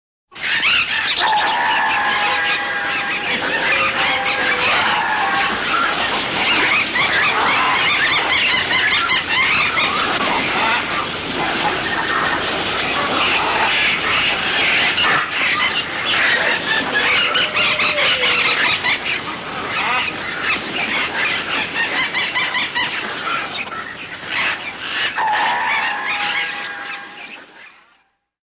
18 Birds.mp3